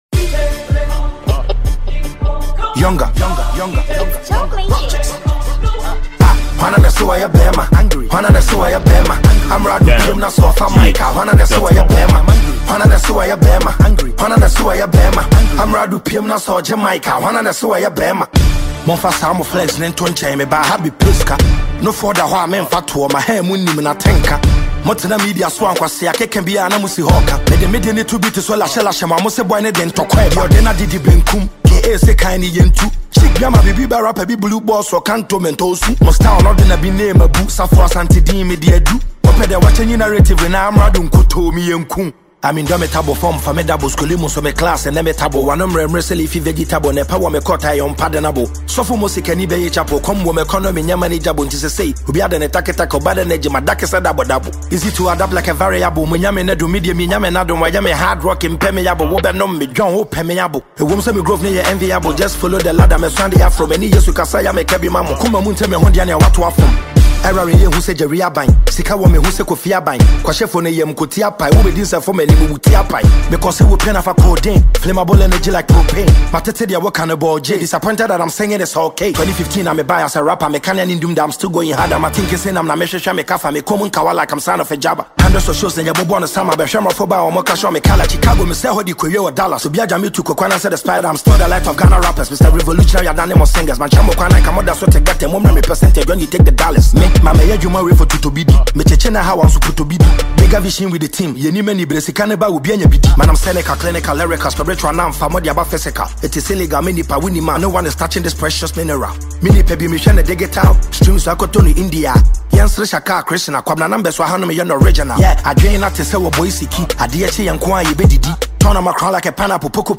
diss tune